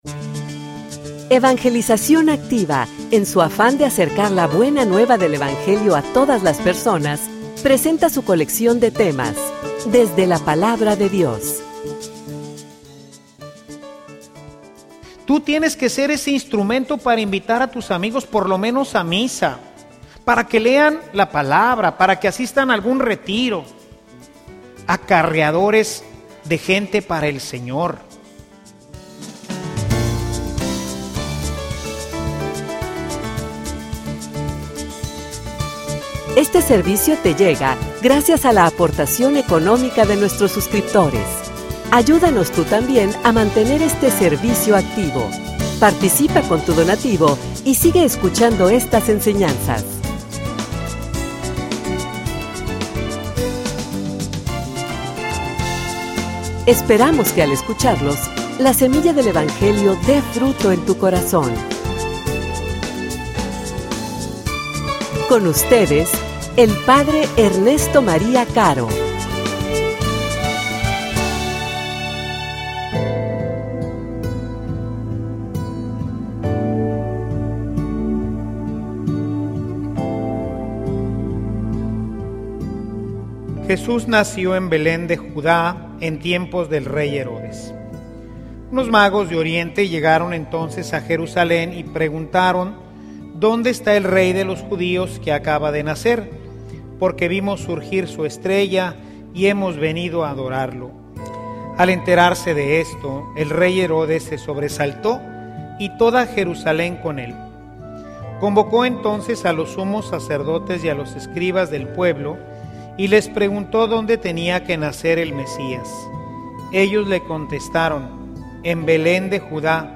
homilia_Tu_debes_ser_esa_estrella.mp3